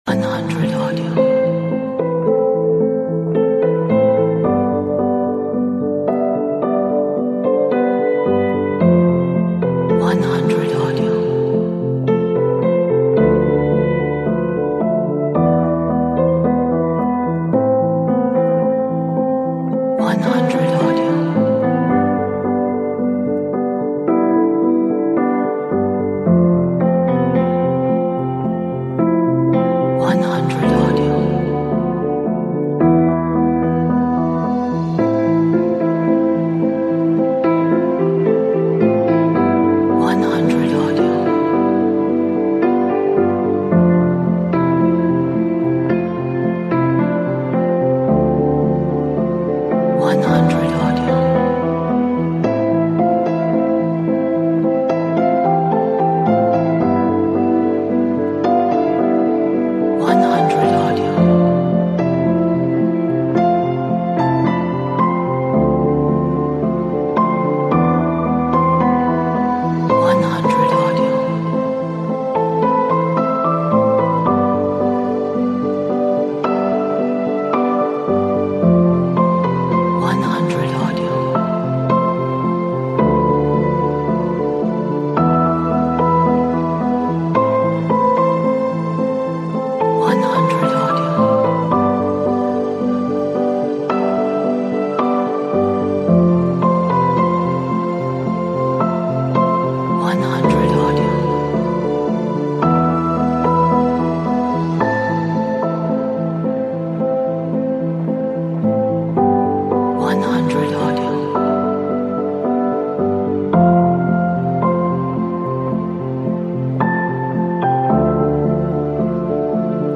A beautiful, gentle and also sad track